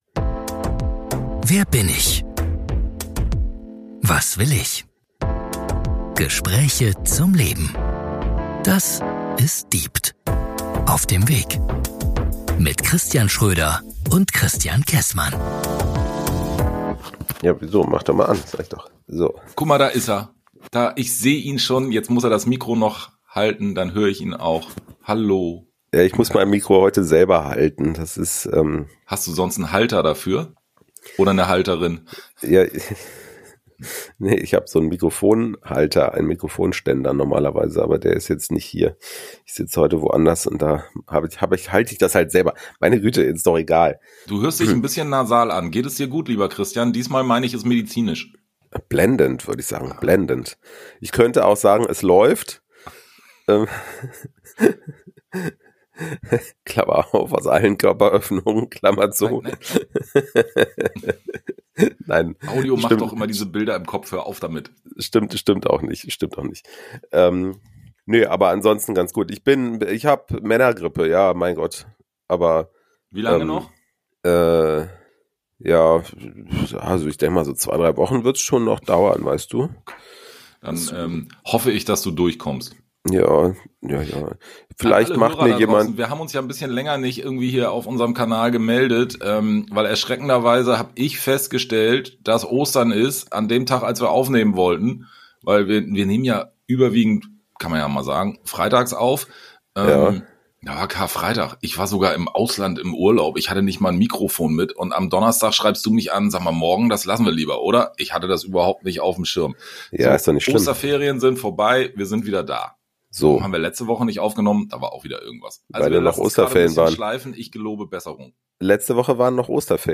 Disclaimer: Wir haben keinen psychologischen oder therapeutischen Background, sondern sprechen über eigene Erfahrungen und Erlebnisse.